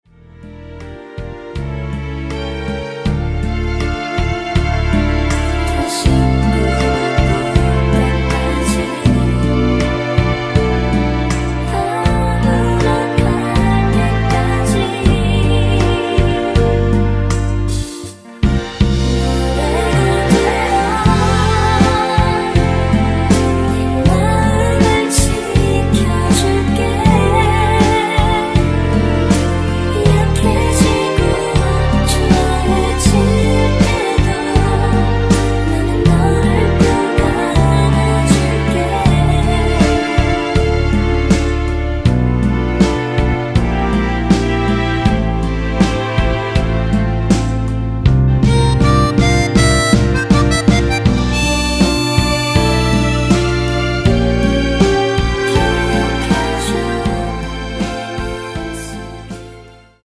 원키에서(+4)올린 코러스 포함된 MR입니다.(미리듣기 확인)
앞부분30초, 뒷부분30초씩 편집해서 올려 드리고 있습니다.